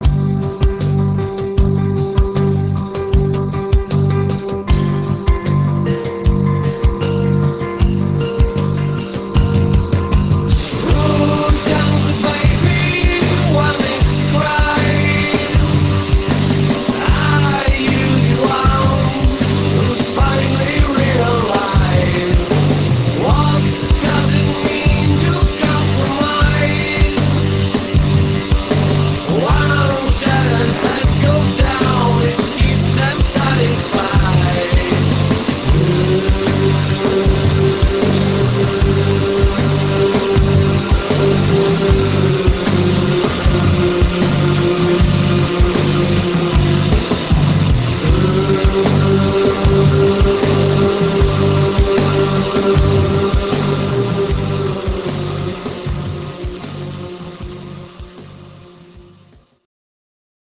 120 kB MONO